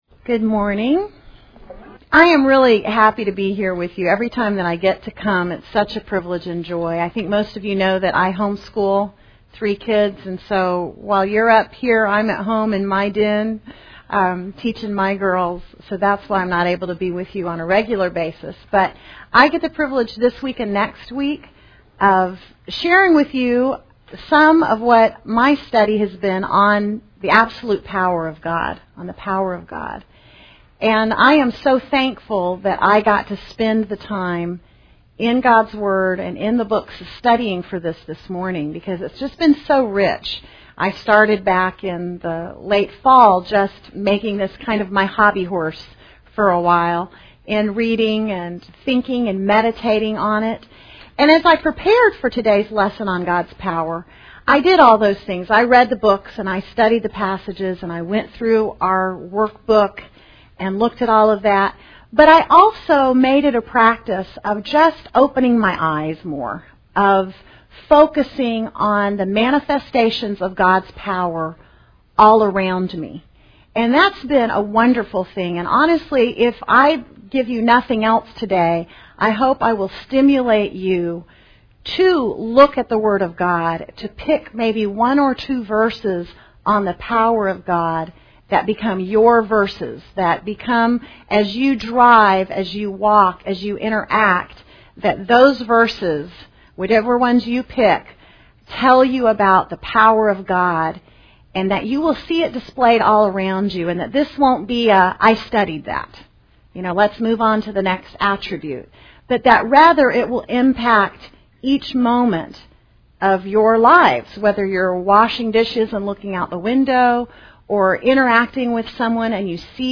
Women Women - Bible Study - The Attributes of God Audio ◀ Prev Series List Next ▶ Previous 8.